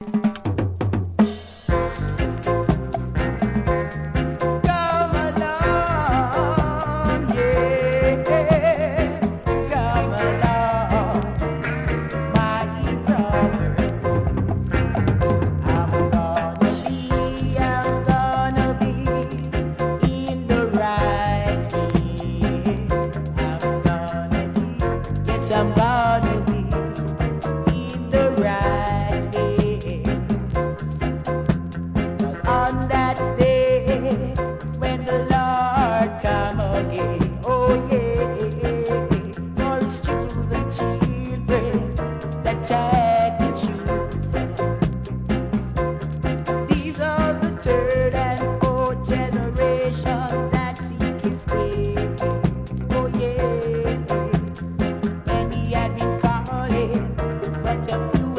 REGGAE
ピアノのはじき出す音が扇情的なコンシャスROOTS!!